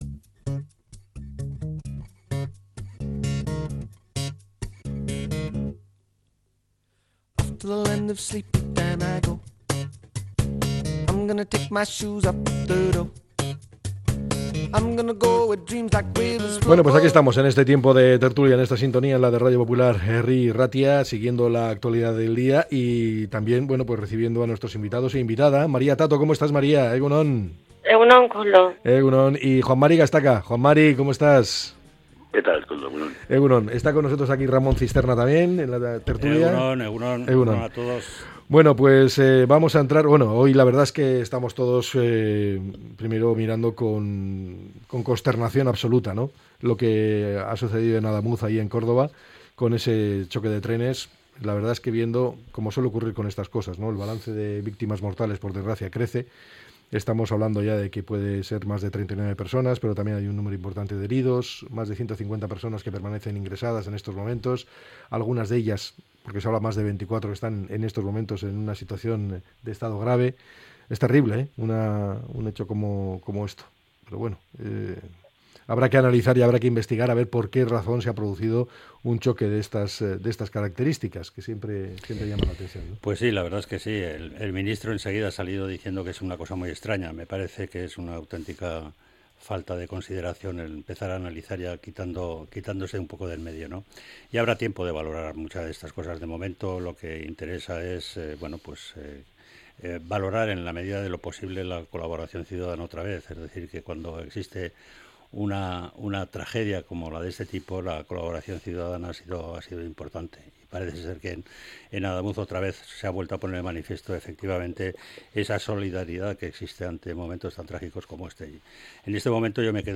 La Tertulia 19-01-26.